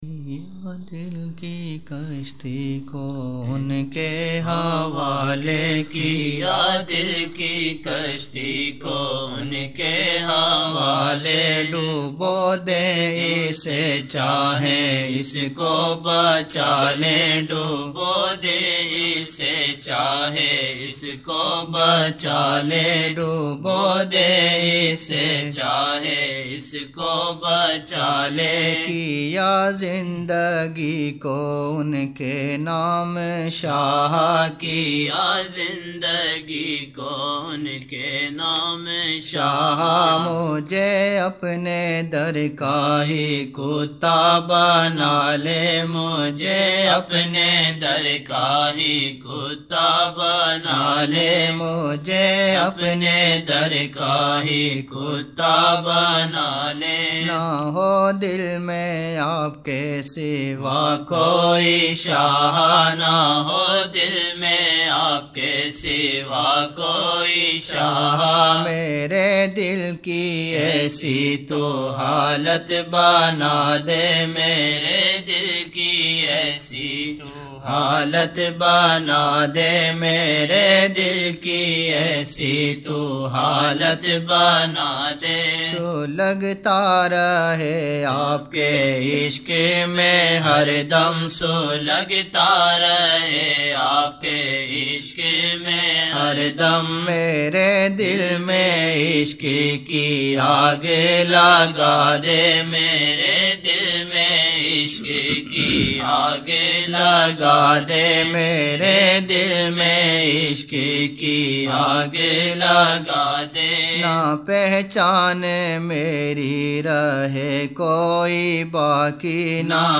12 November 1999 - Maghrib mehfil (4 Shaban 1420)
Naat shareef